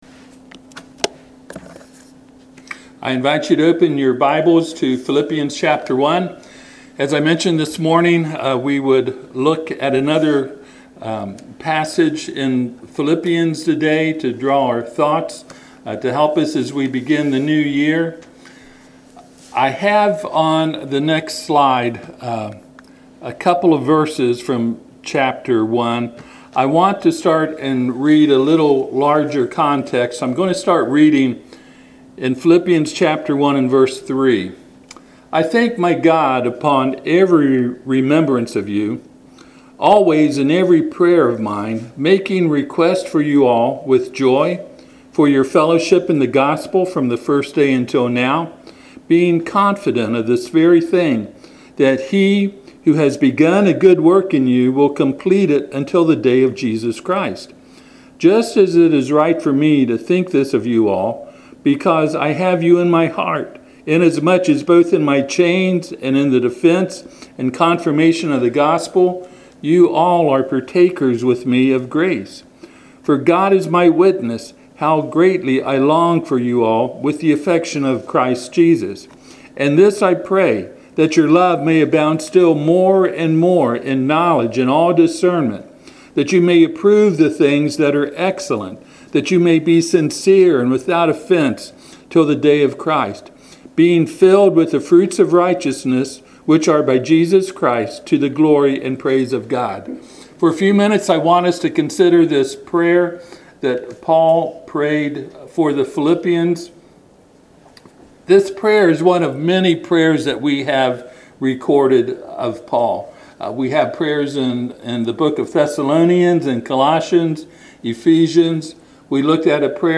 Passage: Philippians 1:9-11 Service Type: Sunday PM